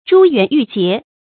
珠圓玉潔 注音： ㄓㄨ ㄧㄨㄢˊ ㄧㄩˋ ㄐㄧㄝ ˊ 讀音讀法： 意思解釋： 比喻詩文圓熟明潔。